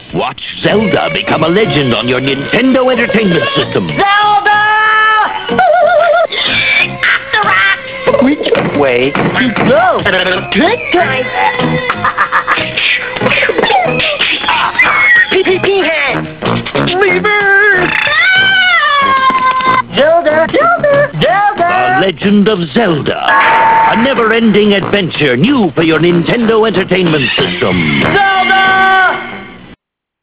He makes all sorts of stupid noises and poses, imitating (I guess) the enemies of the game. Other times, he's calling out "Zelda!!" as if he expects to find her in this dark Seattle warehouse.